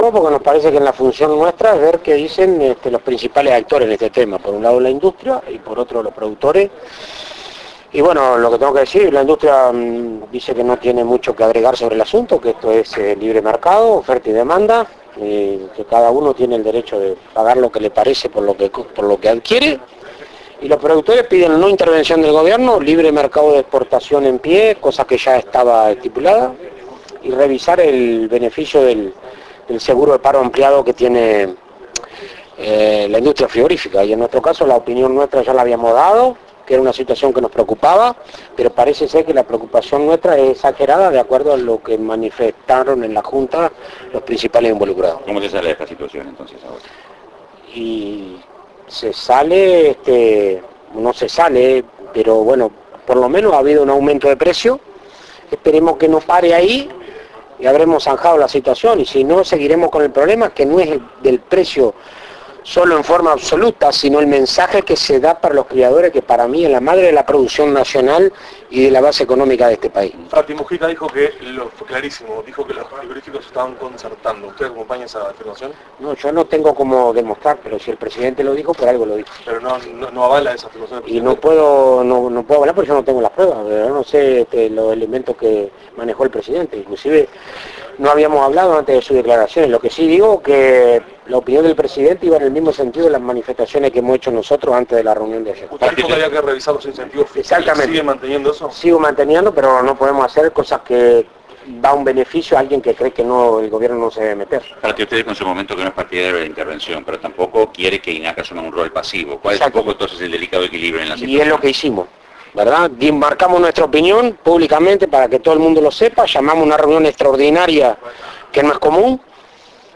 AUDIO Entrevista Fratti.
fratte_entrevista_faena.mp3